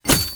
armor_hitted.wav